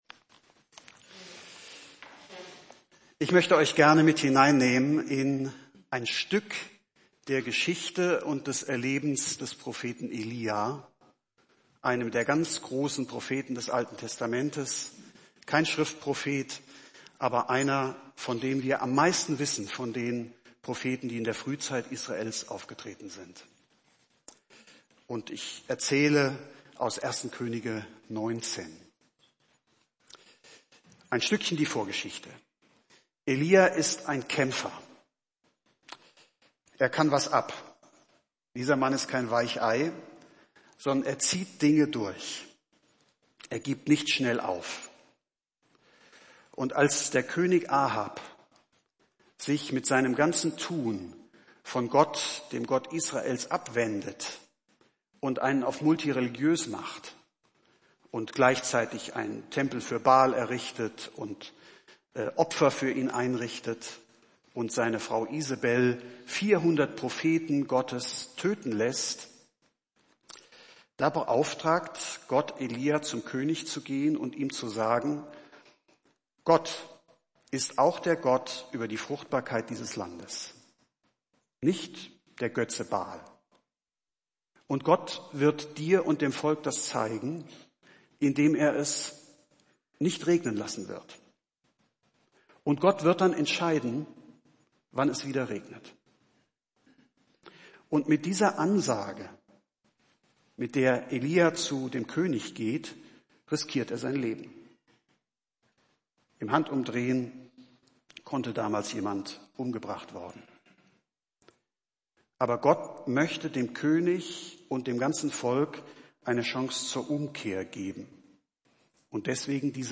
Predigttext: 1. Könige 19, 1-21